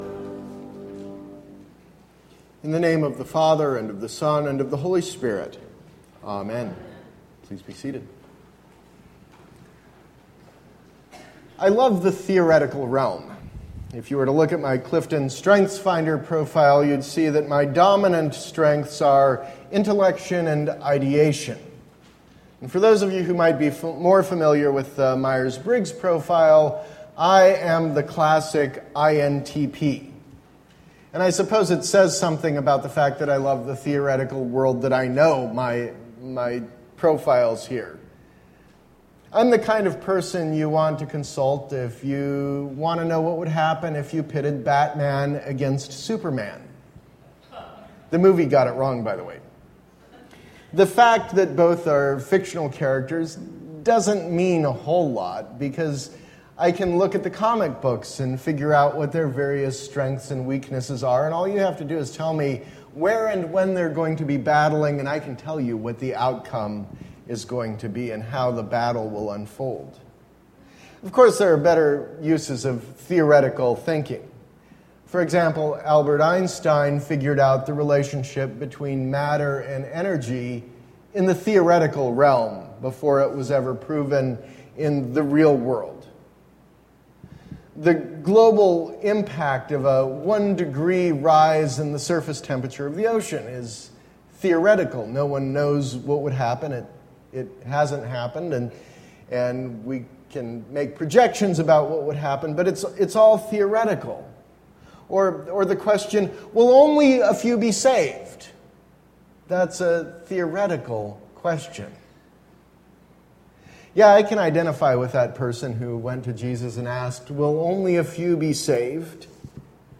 Sermon – August 21, 2016